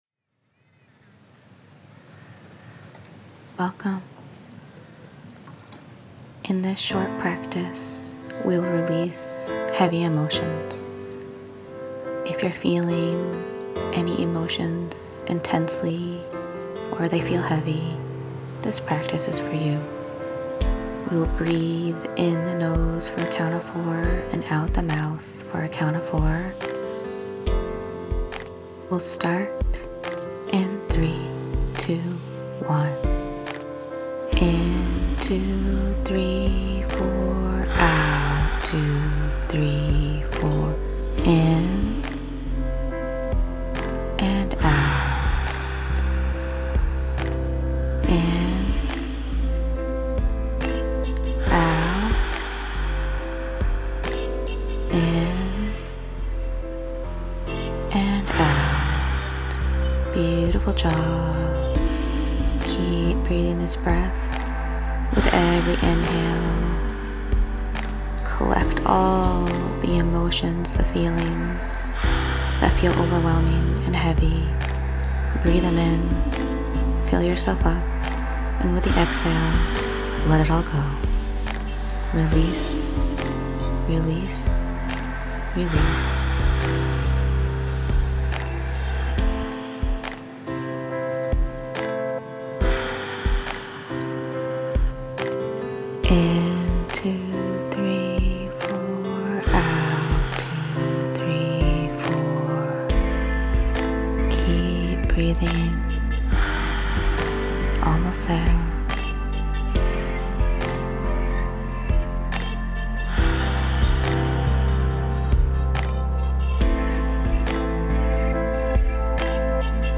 Heart-centered professionally guided Breathwork sessions to calm, clear, and energize.
Easy to follow breaths with intuitively chosen music.